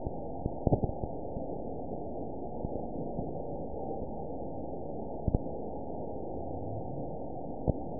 event 922859 date 04/22/25 time 20:17:54 GMT (7 months, 1 week ago) score 8.88 location TSS-AB01 detected by nrw target species NRW annotations +NRW Spectrogram: Frequency (kHz) vs. Time (s) audio not available .wav